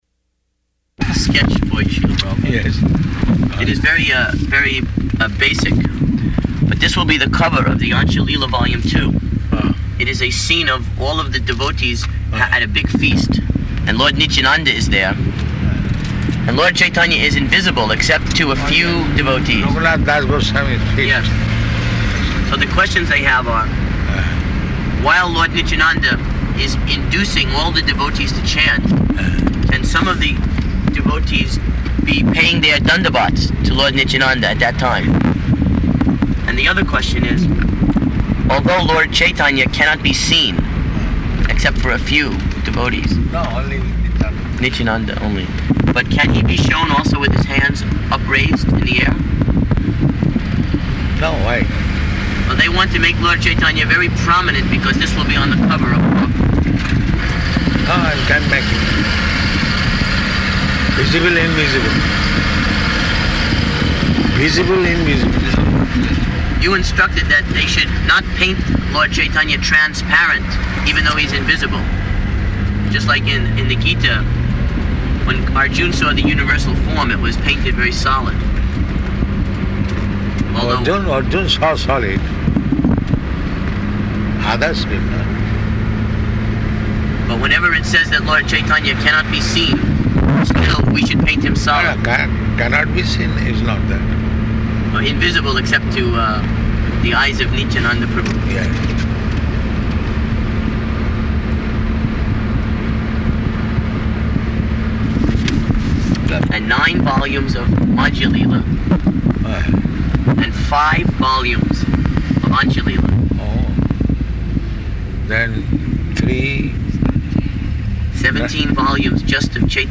Departure Conversation